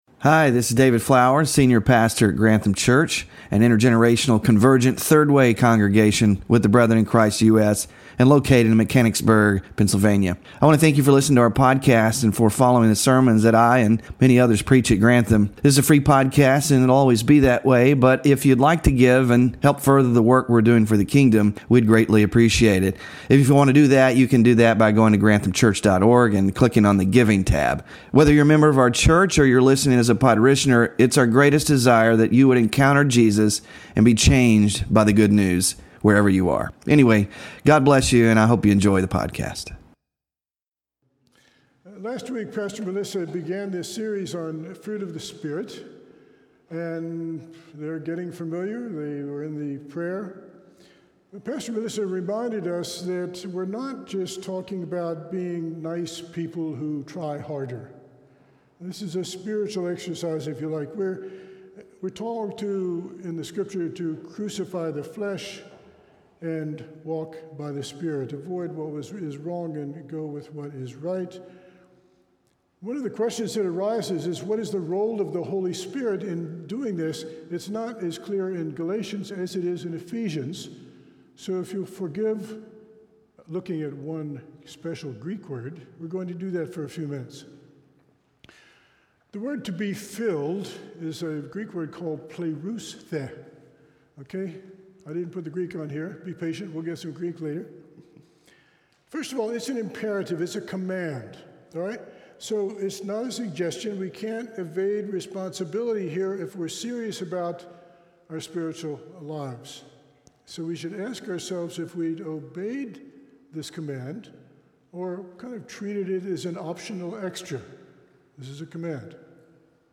FRUIT OF THE SPIRIT WK2-JOY SERMON SLIDES